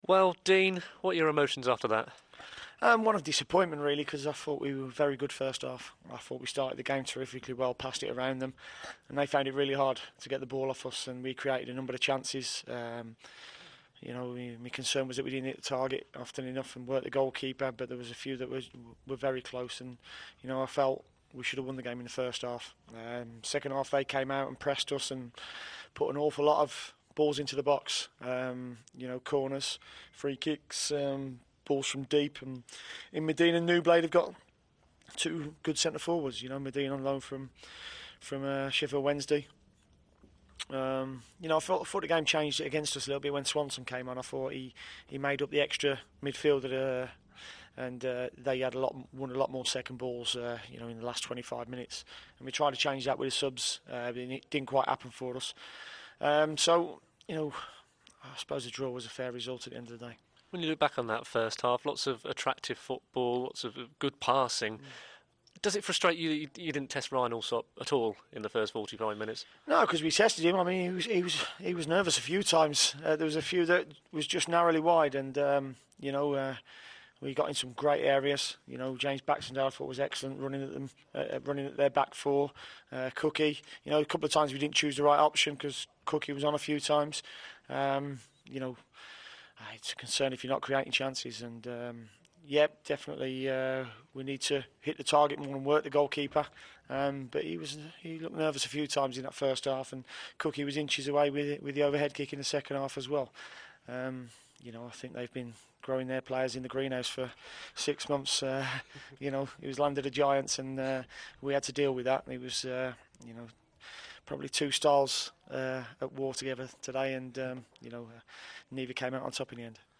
talks to Dean Smith post-match at the Ricoh.